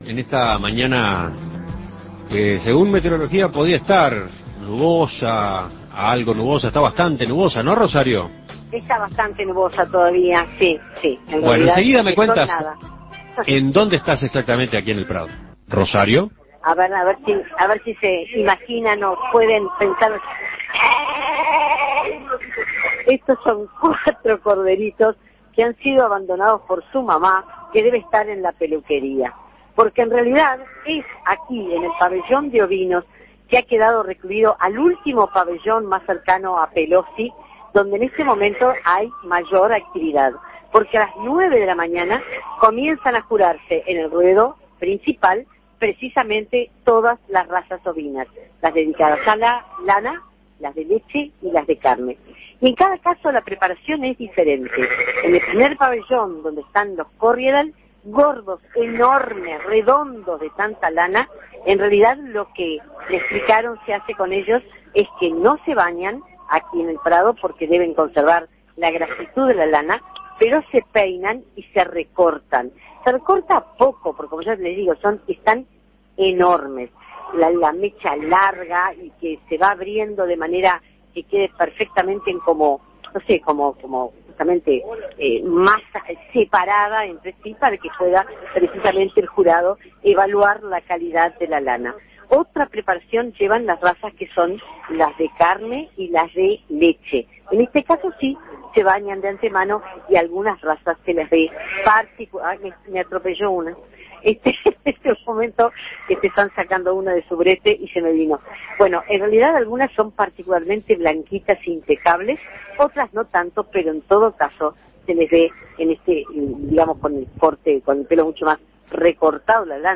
Esquila de ovejas en la Expo Prado 2009